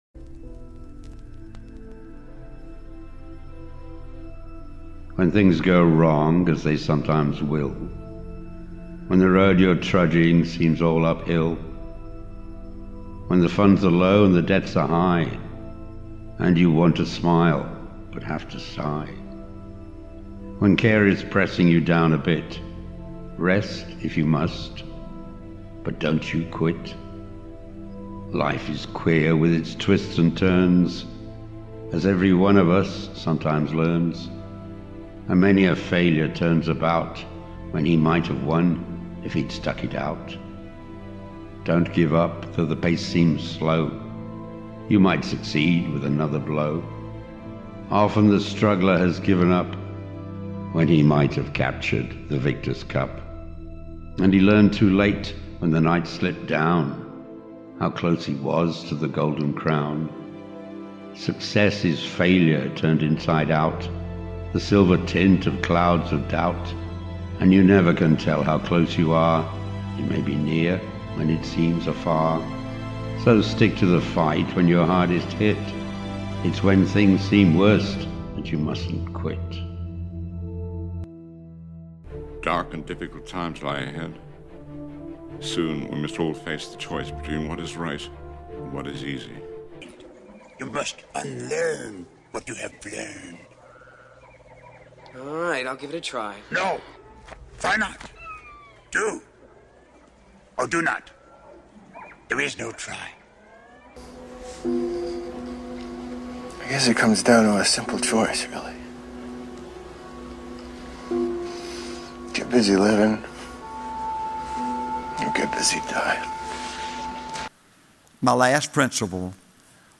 audio file of a famous poem, inspiring quotes from some movies that I like, part of a motivational speech and the part of Eminem’s “Lose Yourself” (also on the playlist) where he says, “Success is my only motherf’ing option, failure’s Not!” It’s around 8 minutes in length, perfect for the drive to the gym.